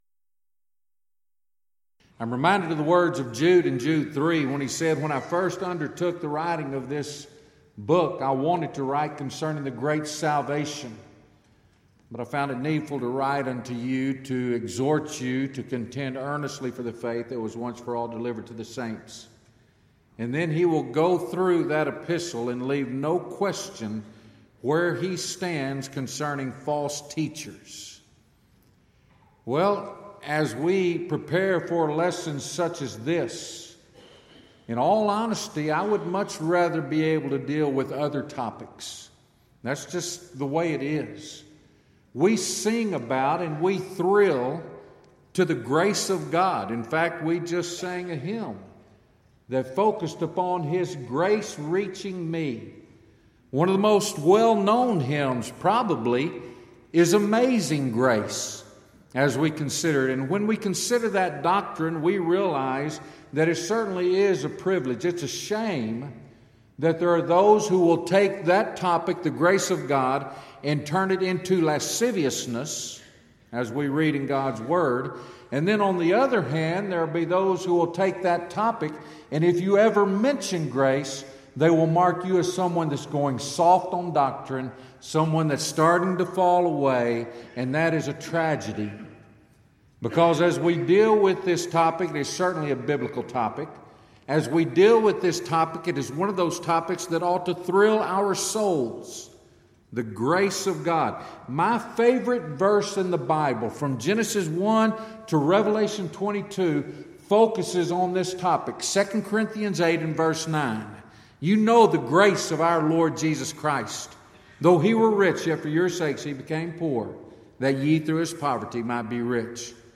If you would like to order audio or video copies of this lecture, please contact our office and reference asset: 2013Southwest08 Report Problems